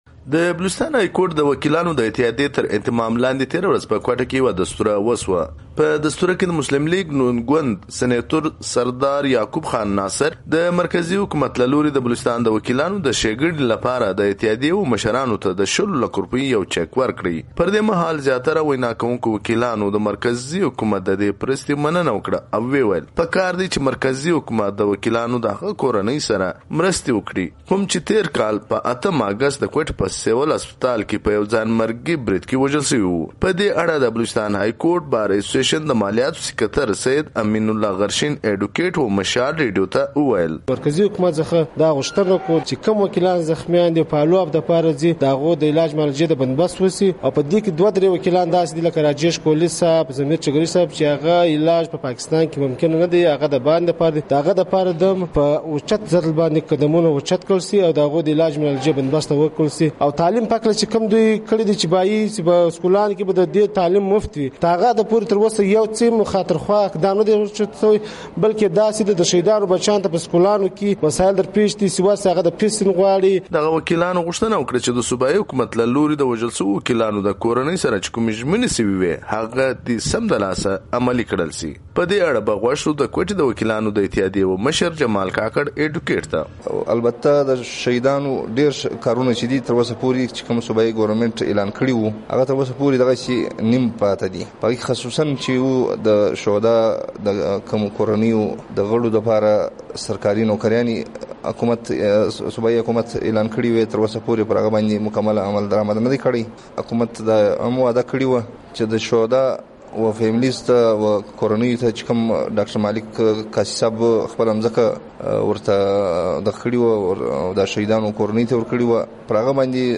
راپور